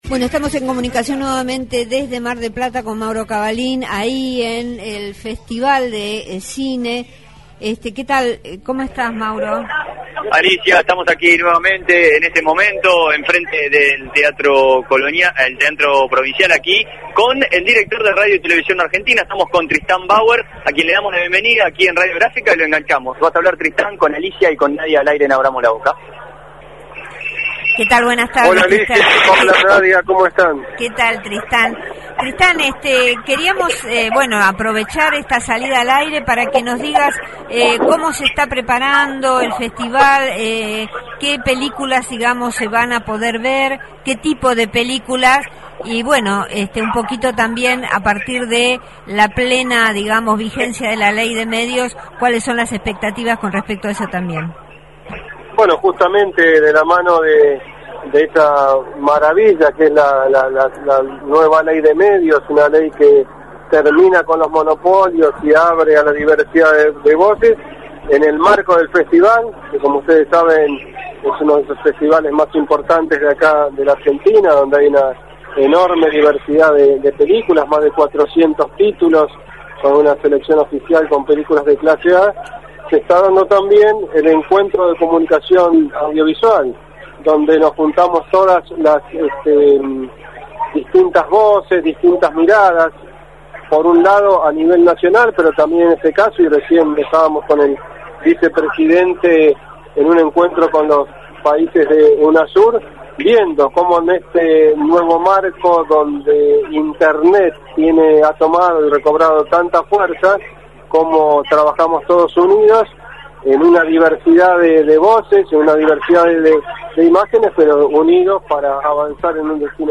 bauer interiorEl galardonado director de cine Tristán Bauer fue entrevistado en Abramos la Boca.